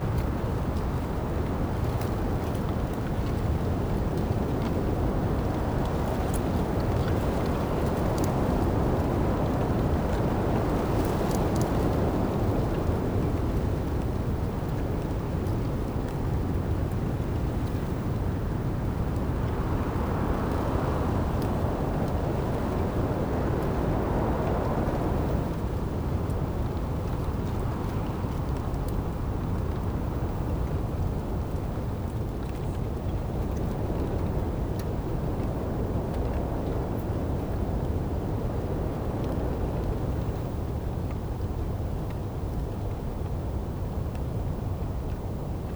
Light wind 1.wav